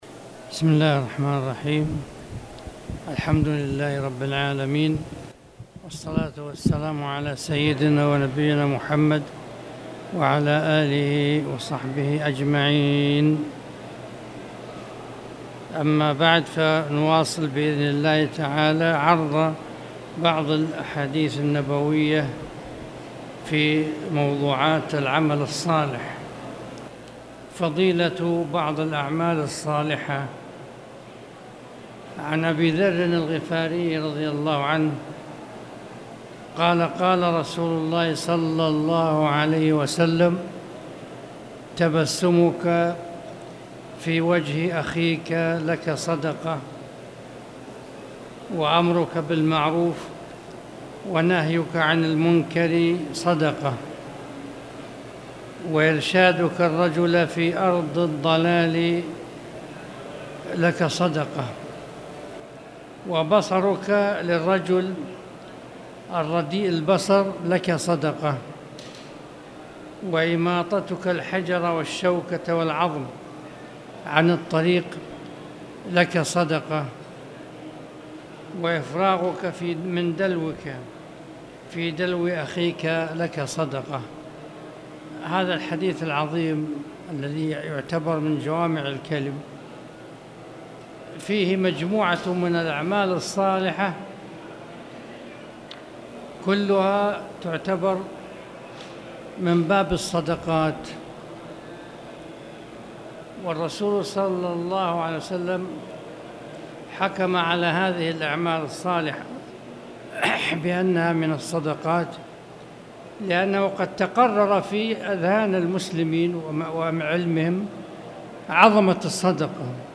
تاريخ النشر ٢٨ محرم ١٤٣٨ هـ المكان: المسجد الحرام الشيخ